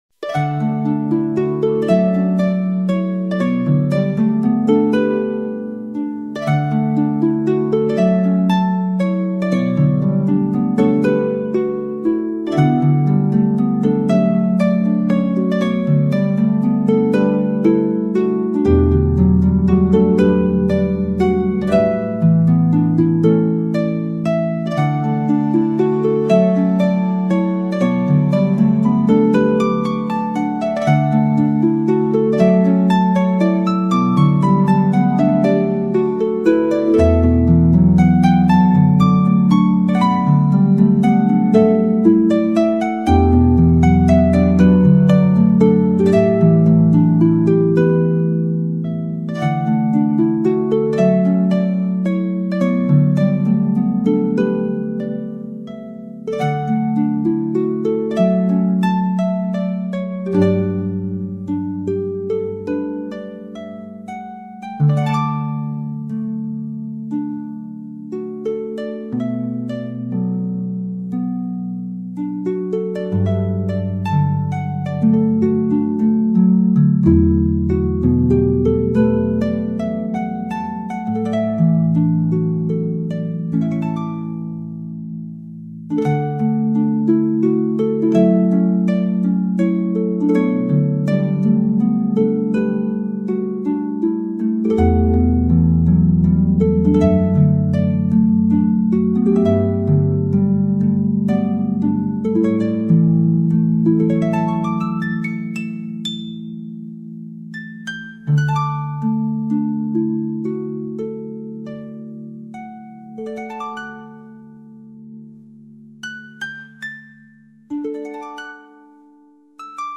Harp Soundscape for a Clear Mind
a pure and simple harp piece